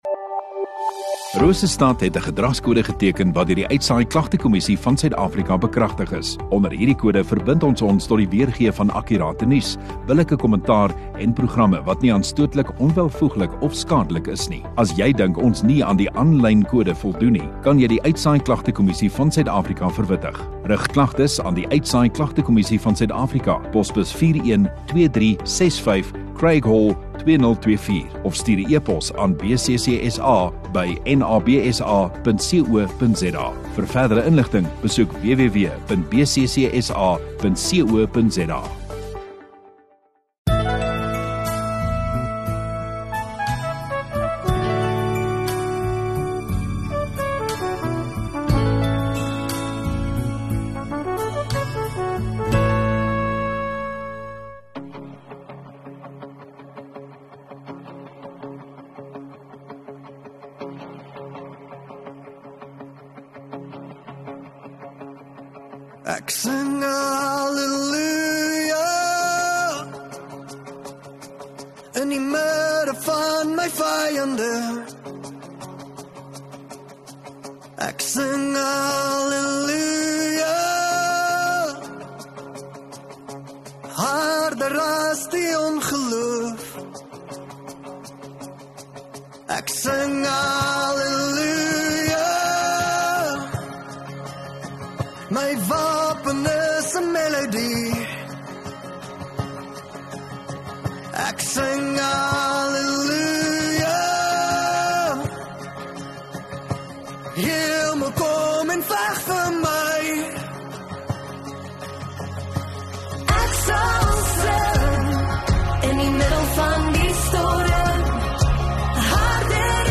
27 Oct Sondagoggend Erediens